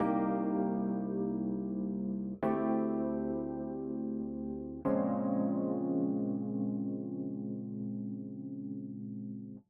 Вроде как rhodes, но все что к меня есть далеки от этого.